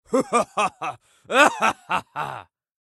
male-laugh-evil.ogg